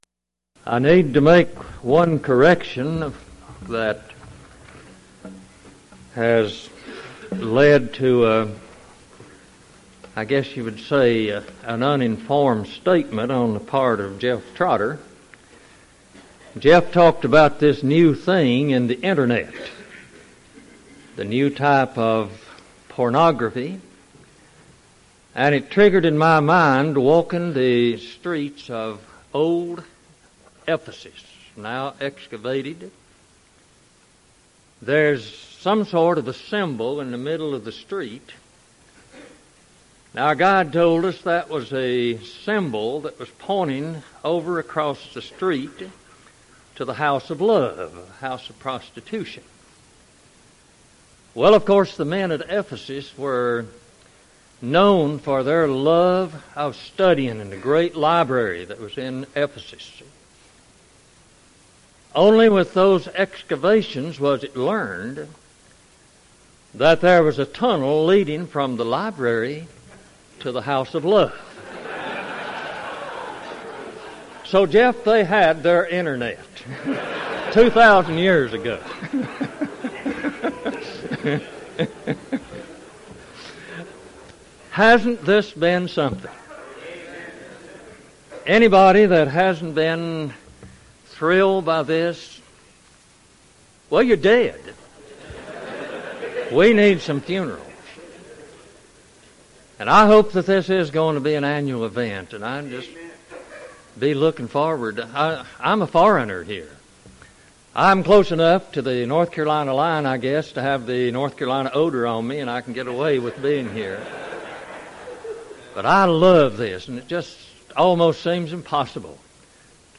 Event: 1999 Carolina Men's Fellowship
lecture